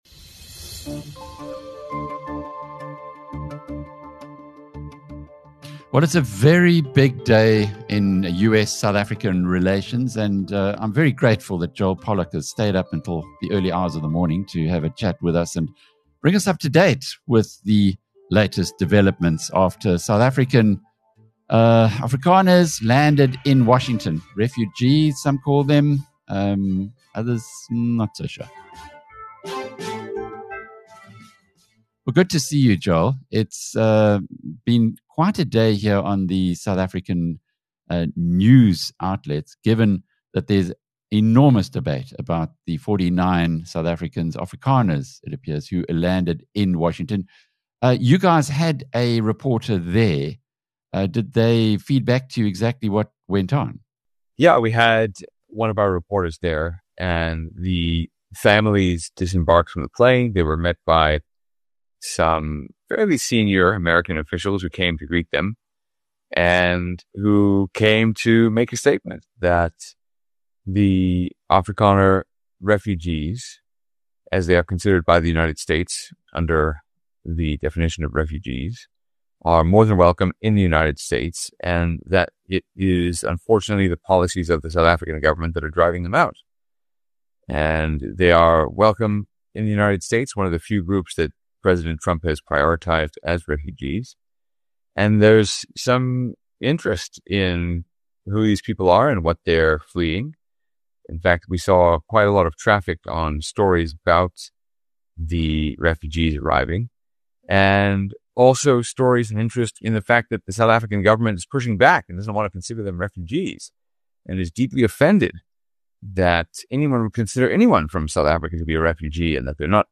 The man who narrowly lost out on becoming the US’s ambassador to South Africa provides a taste of the excitement he would have generated in this feisty interview. Joel Pollak offers an alternative view to the SA government criticism of the first 49 Afrikaner refugees who arrived in Washington yesterday. He also provides insight into how the Trump Administration views the issue in this discussion with BizNews editor Alec Hogg.